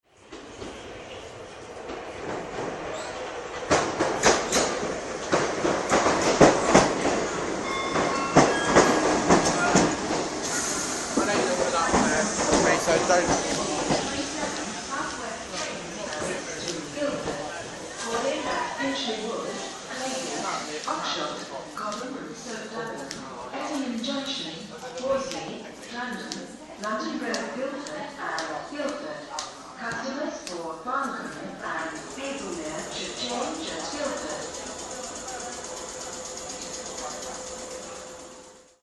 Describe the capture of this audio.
2012-07-20 | Surbiton station - platform 4